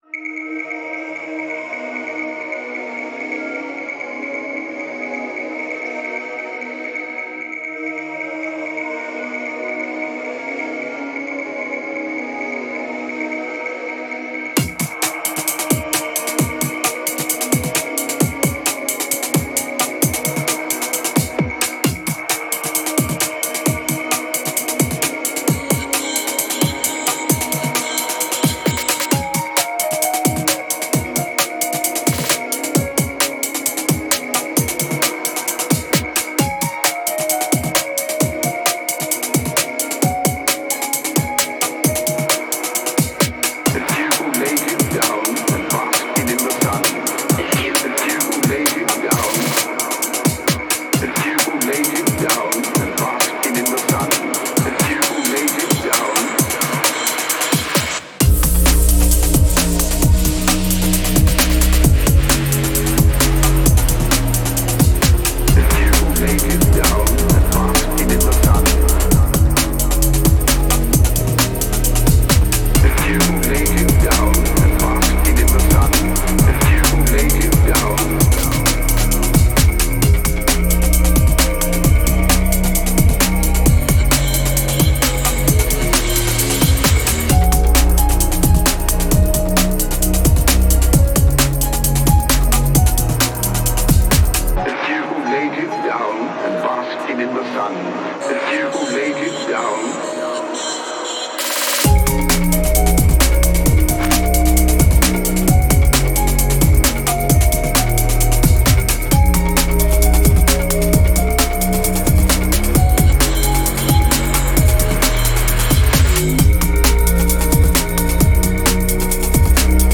a dreamy, nostalgic electronic album.